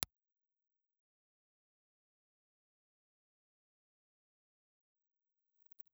Ribbon
Impluse Response file of the Philips 9559. The bass cut inductor has been bypassed.
Philips_9559_IR.wav